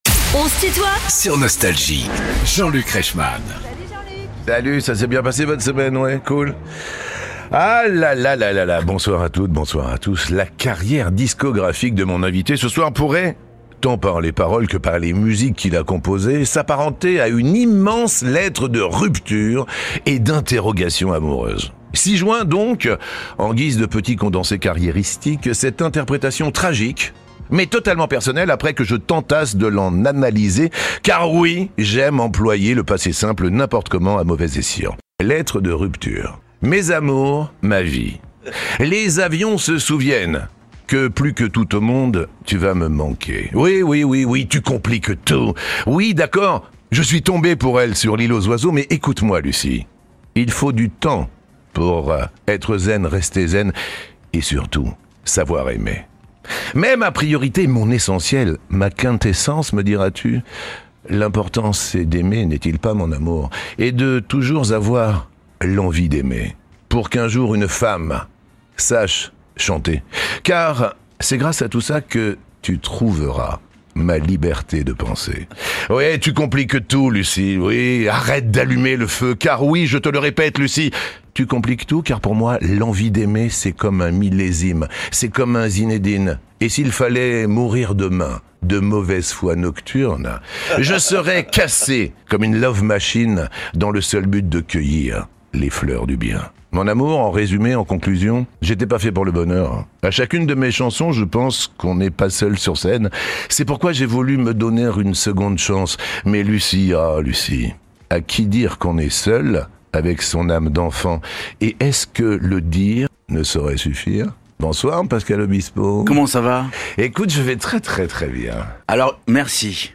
Pascal Obispo est l'invité de Jean-Luc Reichmann dans son émission "On se tutoie ?...", vendredi 21 février de 19h à 20h.